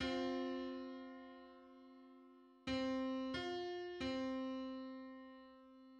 Just: 693/512 = 524.05 cents.
Public domain Public domain false false This media depicts a musical interval outside of a specific musical context.
Six-hundred-ninety-third_harmonic_on_C.mid.mp3